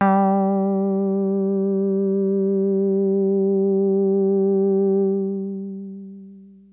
标签： FSharp4 MIDI音符-67 罗兰-SH-2 合成器 单票据 多重采样
声道立体声